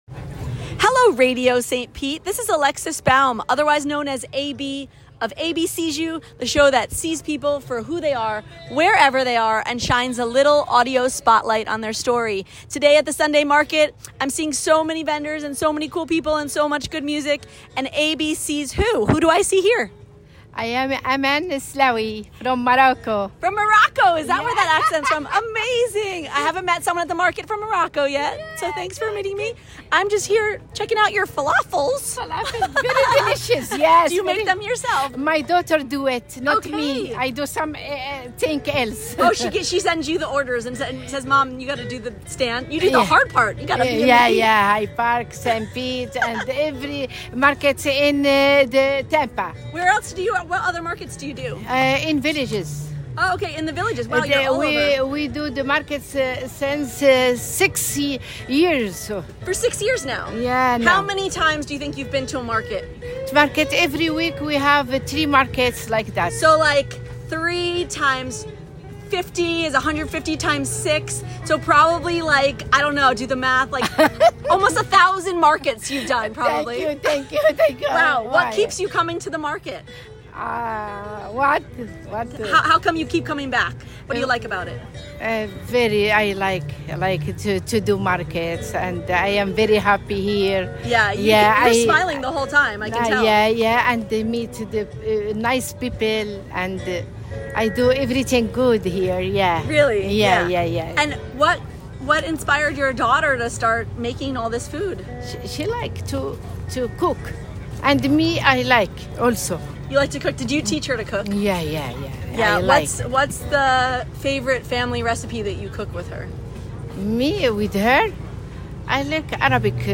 at the Sunday Market